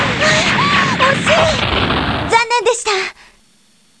しかし、クリアできないときは得点によってモーリンのコメントが変わる。